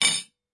餐具的声音 " 大叉子6
Tag: 餐具